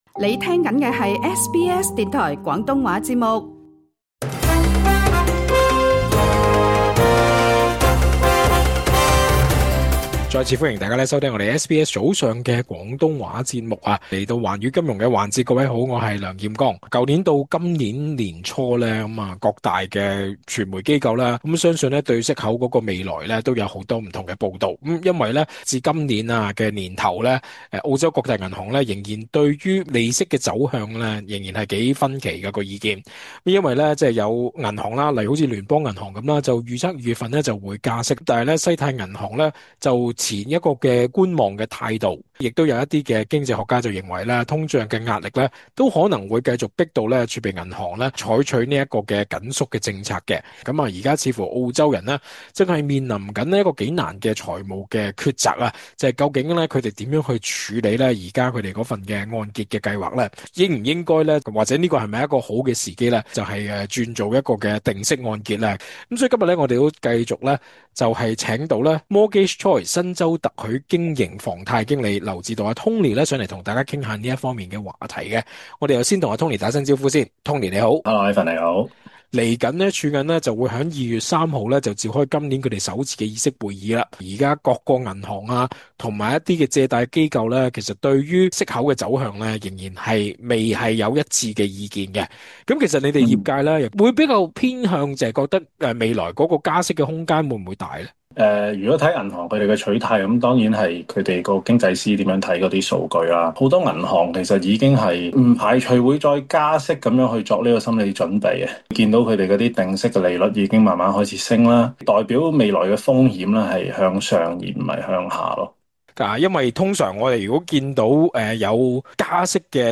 詳盡訪問： LISTEN TO 【通脹擴大、加息機會增】投資者及業主如何部署？